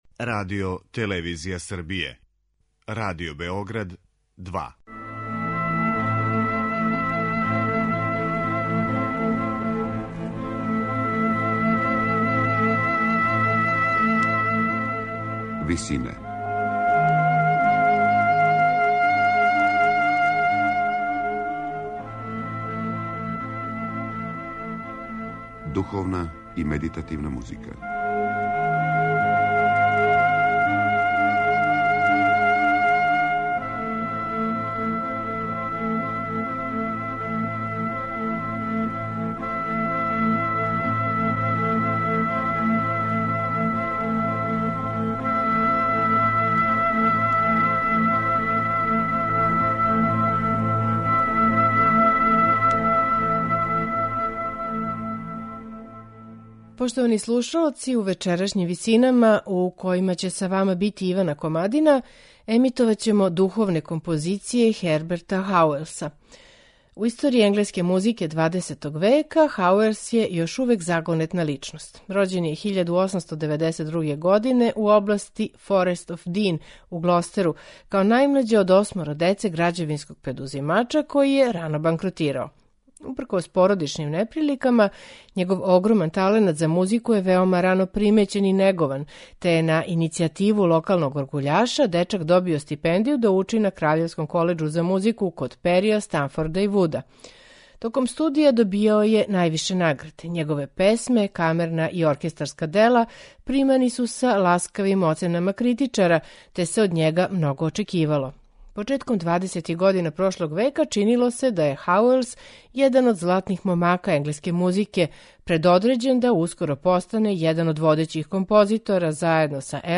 духовна дела настала током шесте и седме деценије 20. века